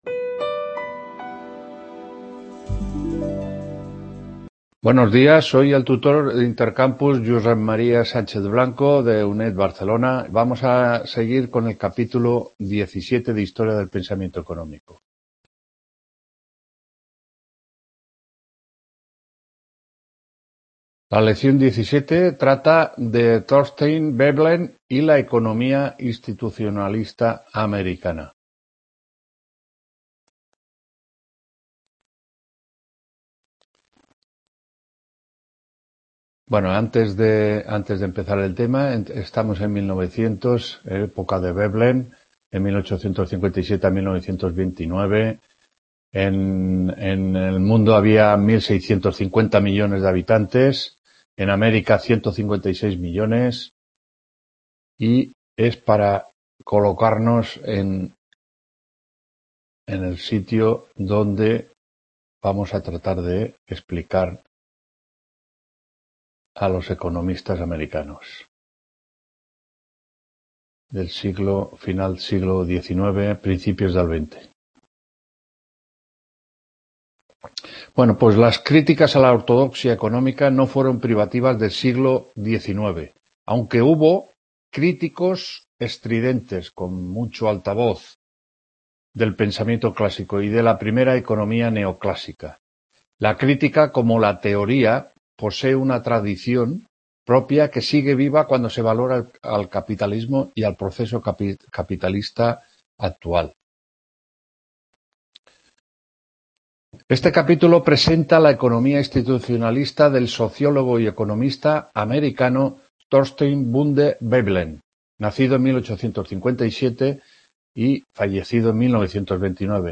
4ª TUTORÍA HISTORIA DEL PENSAMIENTO ECONÓMICO 14-12-19…